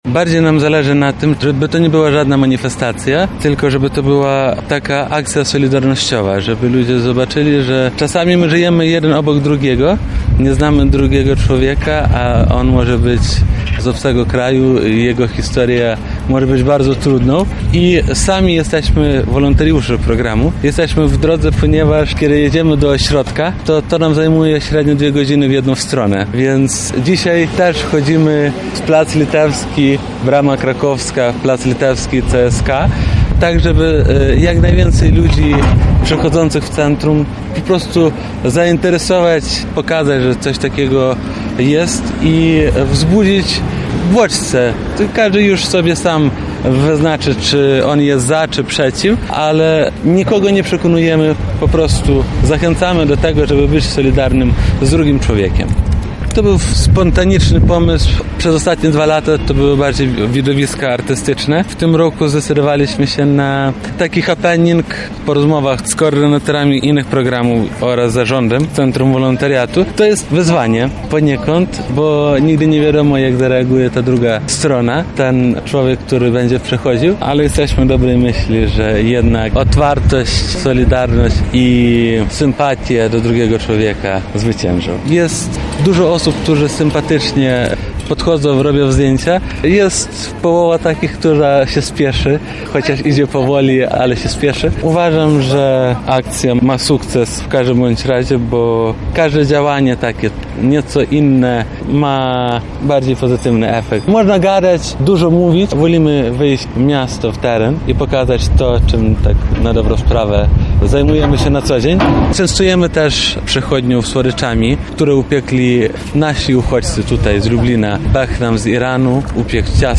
Opublikowano w Aktualności, Audycje, Centrum Uwagi, Wydarzenia